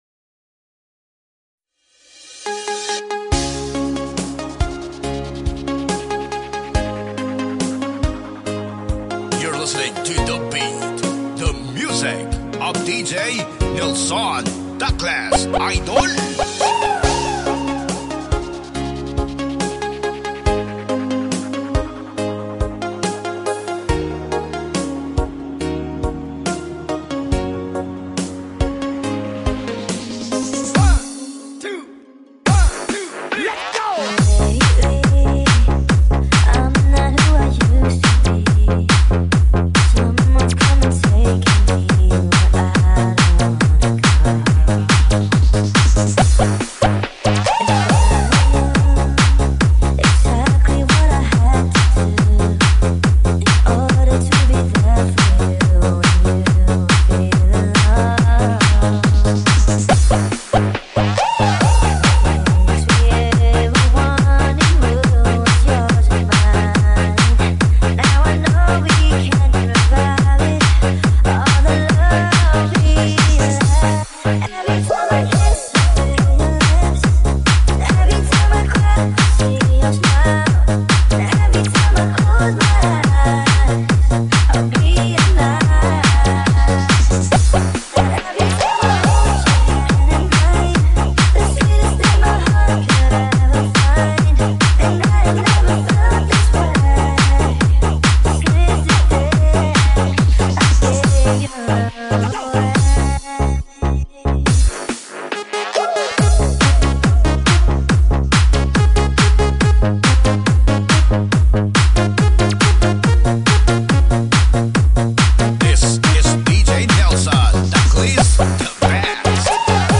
HARDTEK DISCO REMIX